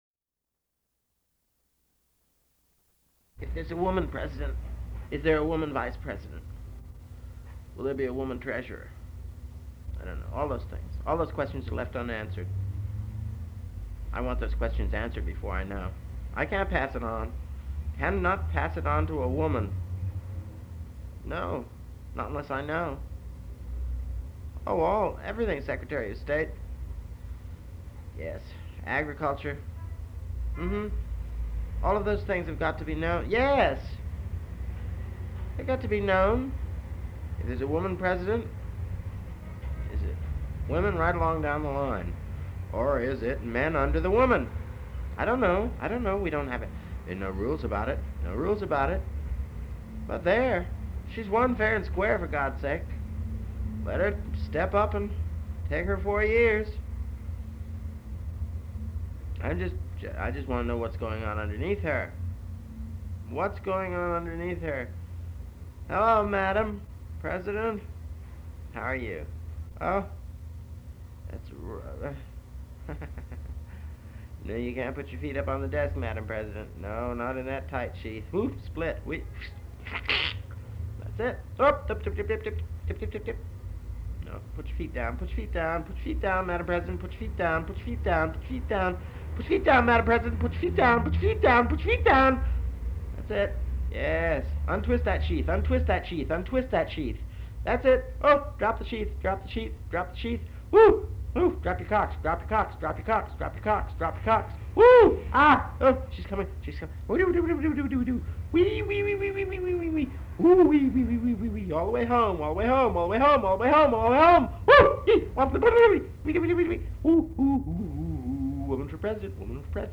WOMAN FOR PRESIDENT! An unreleased Dion McGregor somniloquy, recorded a half century before Hillary Clinton's run for the USA's top office.